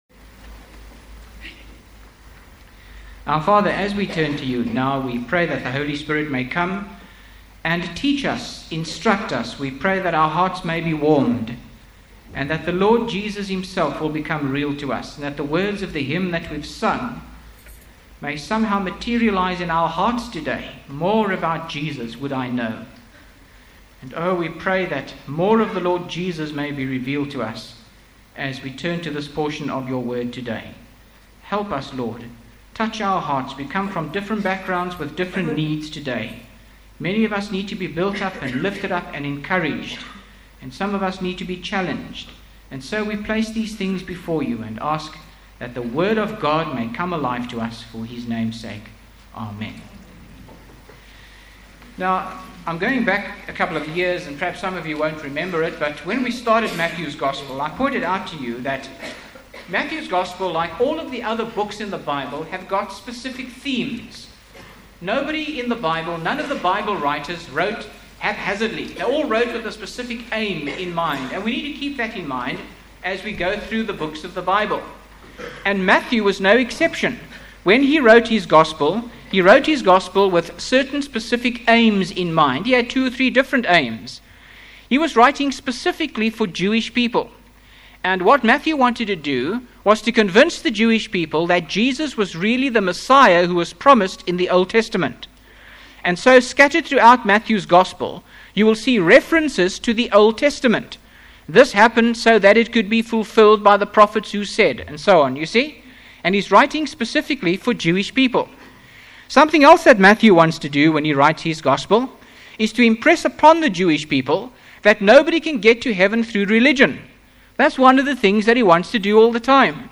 by Frank Retief | Jan 27, 2025 | Frank's Sermons (St James) | 0 comments